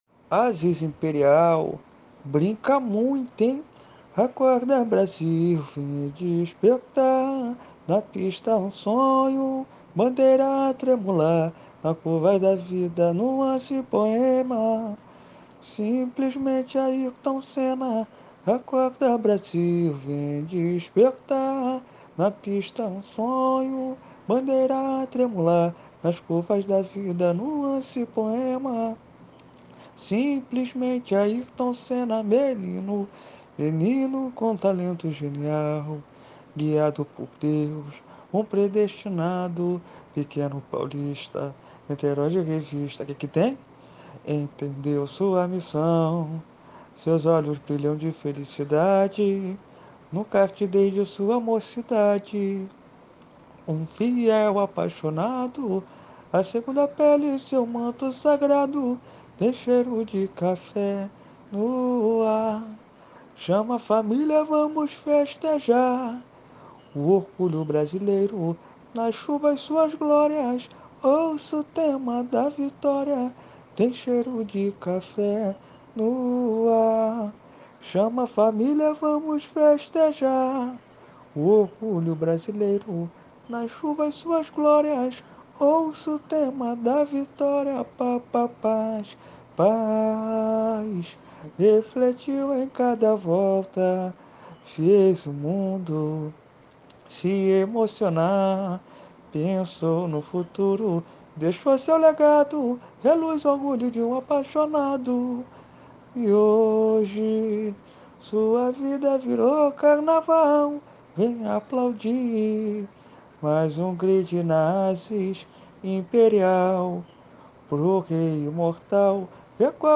Samba  08